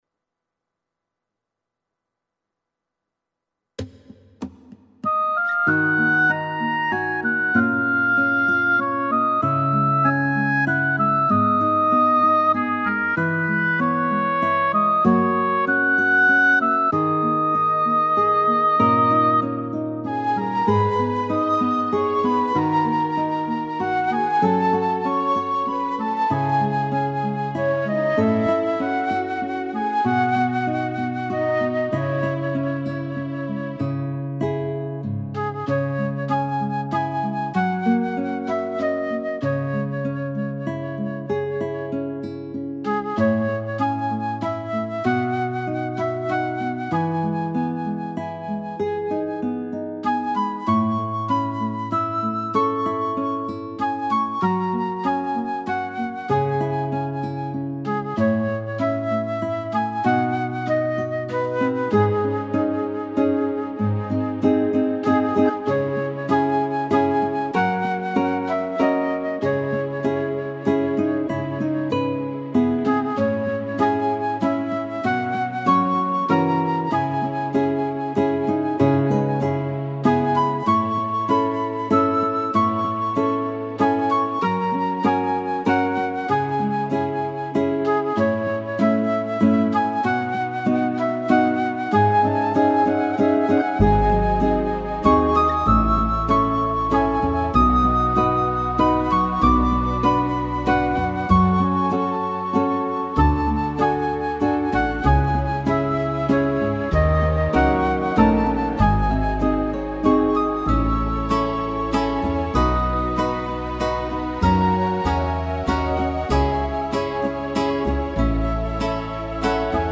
•   Beat  03.
(C#m)